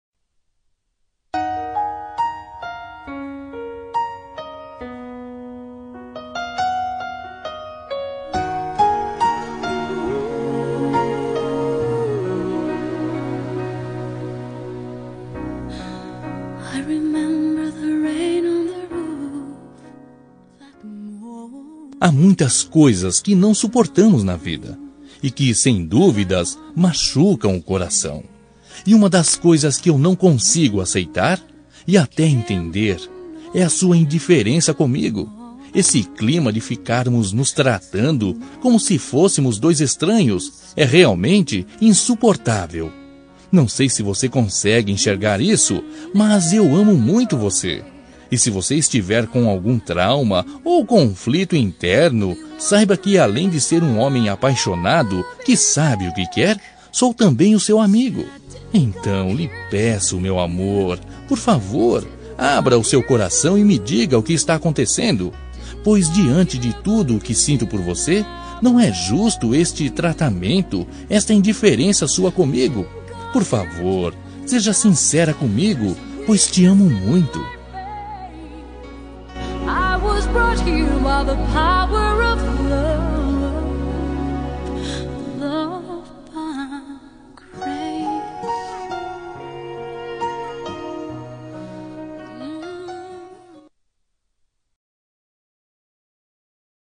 Toque para Não Terminar – Voz Feminina – Cód: 473 – Indiferença